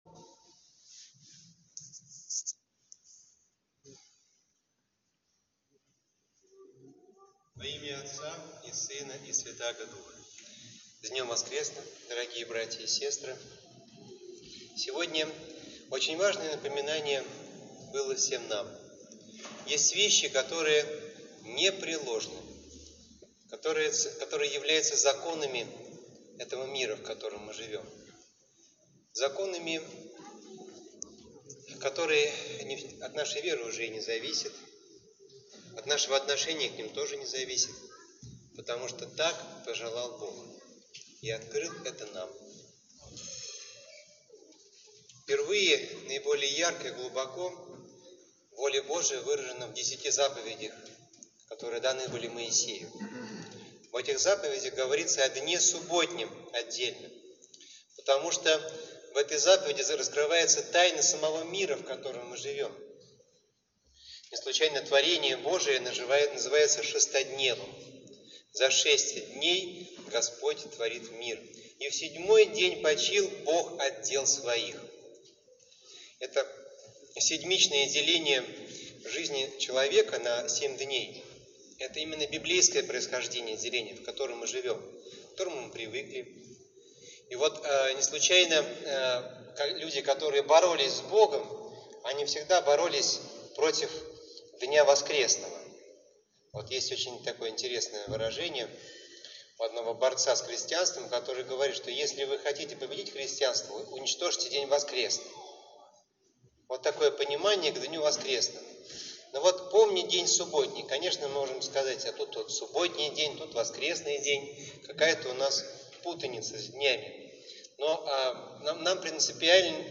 Проповедь 13.12.2020 (аудио)